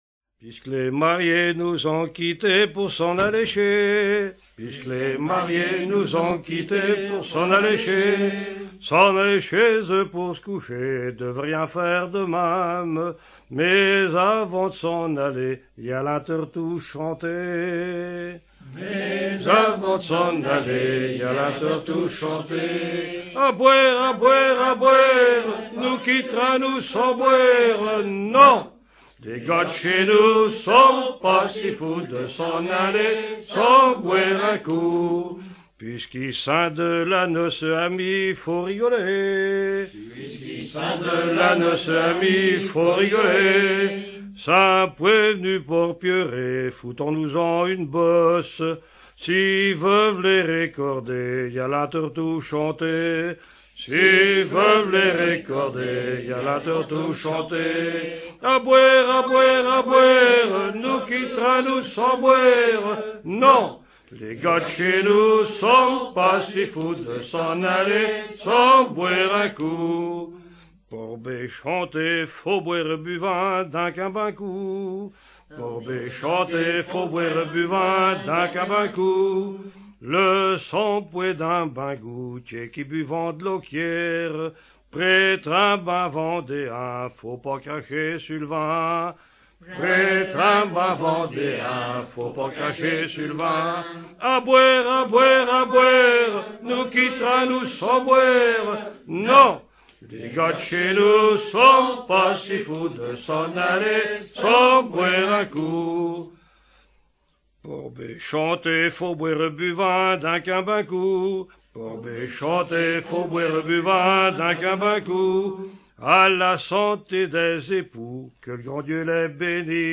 Thème : 0125 - Chants brefs - De noces